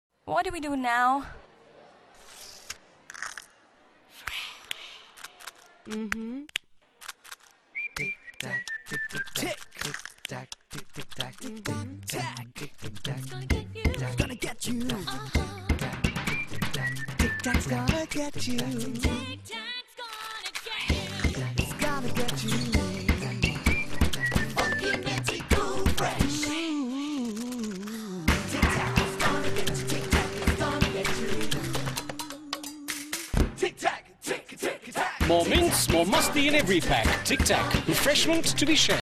File Type : Tv confectionery ads